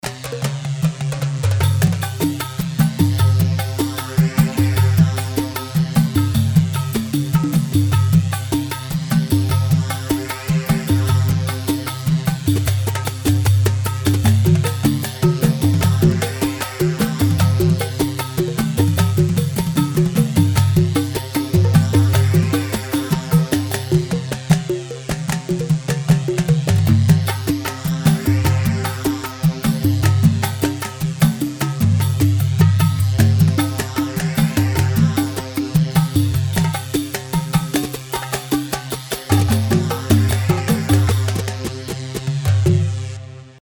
Rhumba 4/4 152 رومبا
Rhumba-152-mix.mp3